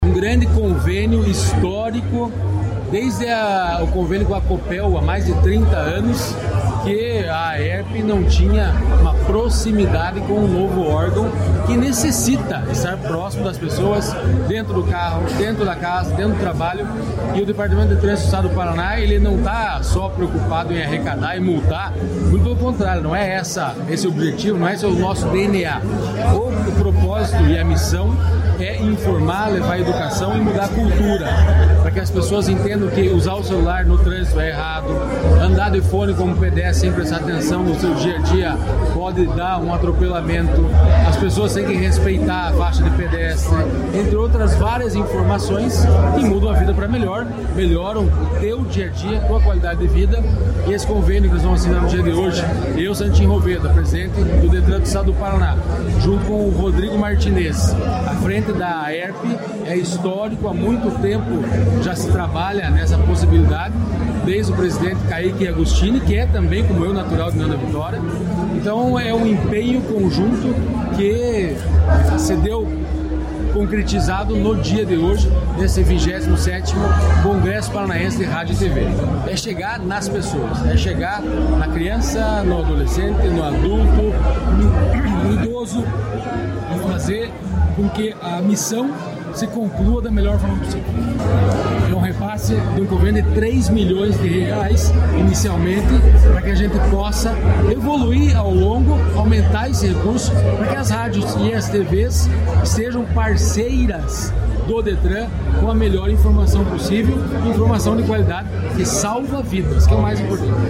Sonora do diretor-presidente do Detran-PR, Santin Roveda, sobre o convênio do Estado com a AERP